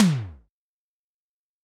Index of /90_sSampleCDs/300 Drum Machines/Boss Dr Pad - 1987/Boss Dr Pad Ableton Project/Samples/Imported